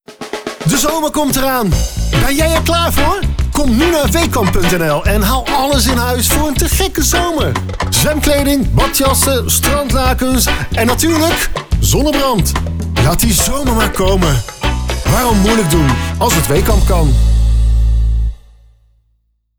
Een aantal voorbeelden van mijn voice-over opdrachten